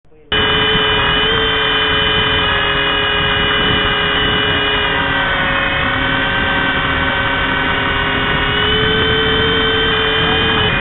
Heli flight
News Twitter Handouts Syllabus Exhibits Lab Exams Links Heli flight This is the movie recorded on Wedensday, July 3 in Hall A. The heli had more interest in the slides.
heli_class.ogg